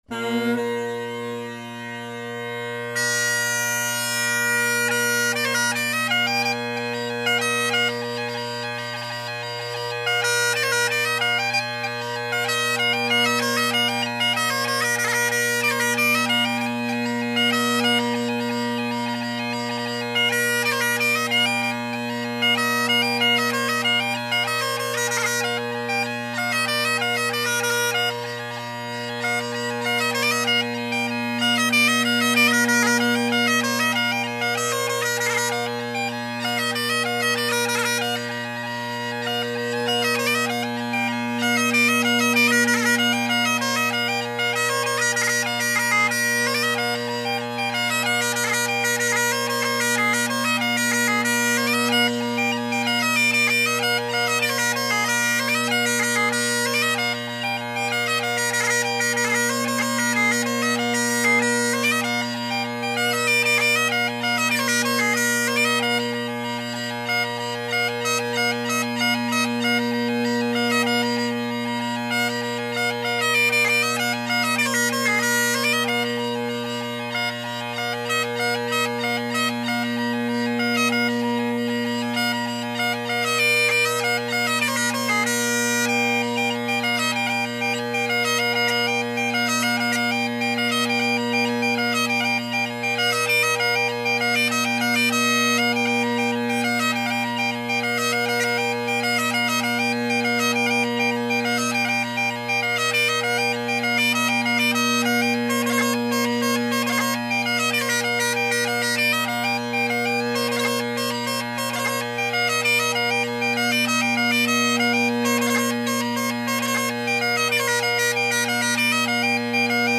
Great Highland Bagpipe Solo
and a few Irish tunes for good measure (the first 2 in the wrong key, the last 2 messed up because it has been forever):